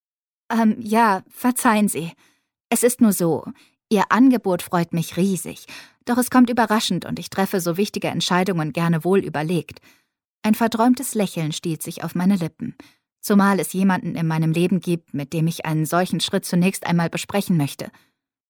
German voice over